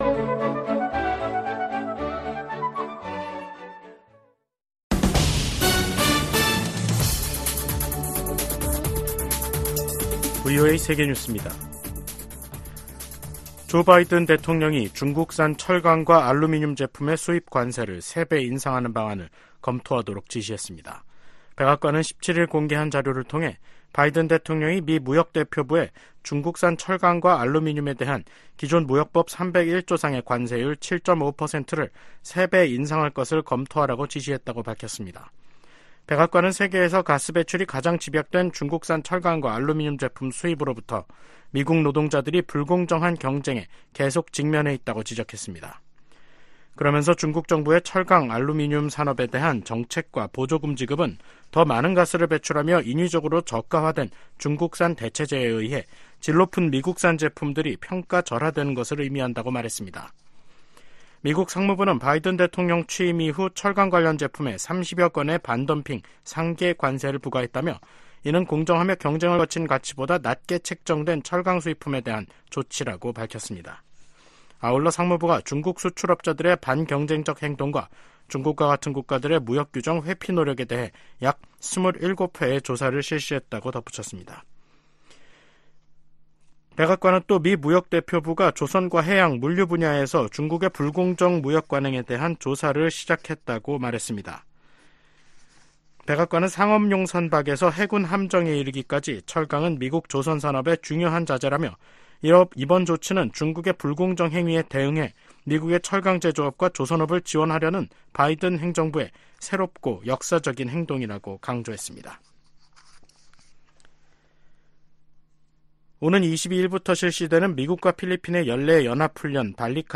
세계 뉴스와 함께 미국의 모든 것을 소개하는 '생방송 여기는 워싱턴입니다', 2024년 4월 17일 저녁 방송입니다. '지구촌 오늘'에서는 미국과 중국의 국방장관이 약 18개월 만에 회담하고 두 나라 군 사이 현안들을 논의한 소식 전해드리고, '아메리카 나우'에서는 도널드 트럼프 전 대통령의 ‘성추문 입막음’ 의혹에 관한 형사재판에서 일부 배심원이 선정된 이야기 살펴보겠습니다.